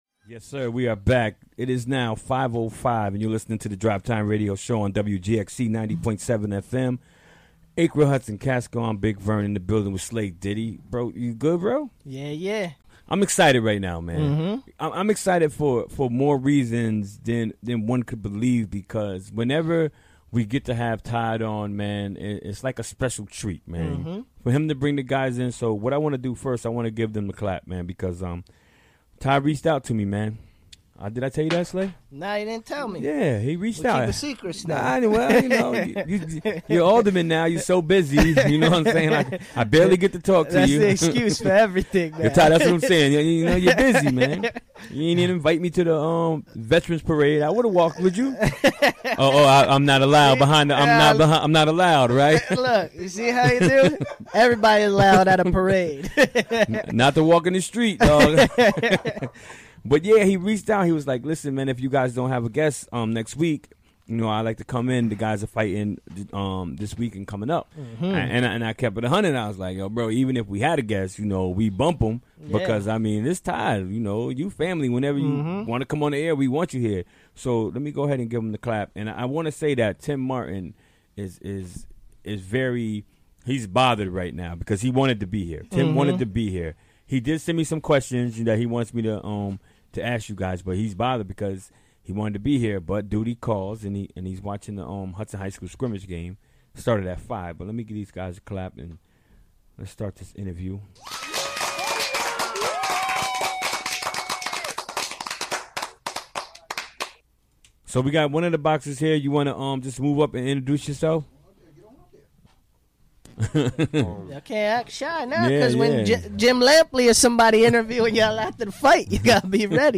Recorded during the WGXC Afternoon Show Wednesday, November 15, 2017.